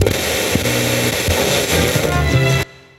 80BPM RAD4-R.wav